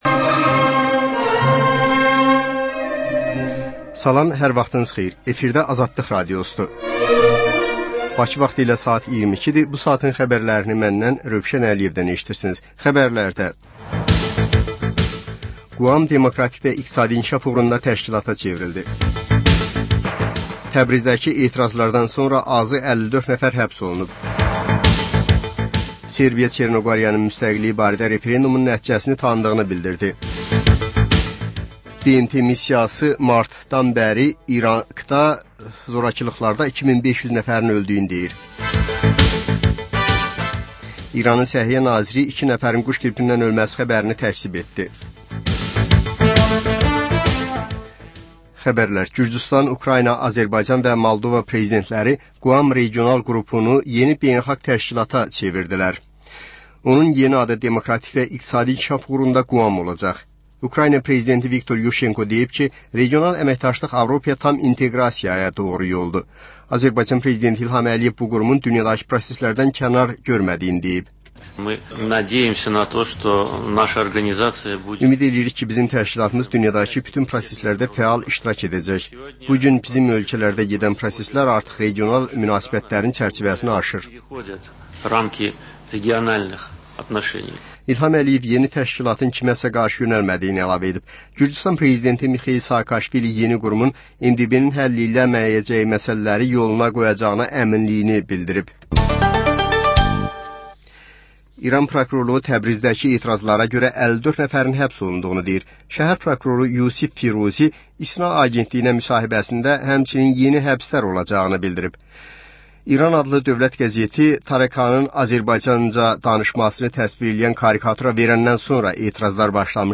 Xəbərlər, reportajlar, müsahibələr. Və: Şəffaflıq: Korrupsiya barədə xüsusi veriliş.